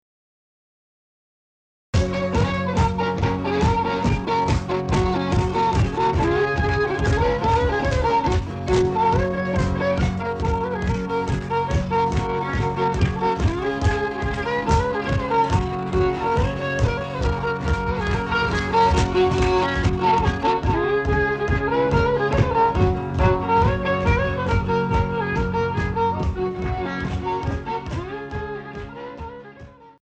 Virginia Reel
violin
rhythm guitar